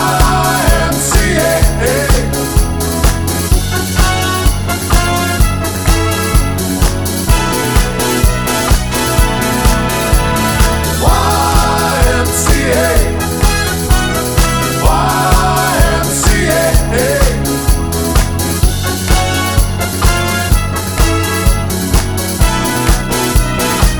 Two Semitones Down Disco 3:44 Buy £1.50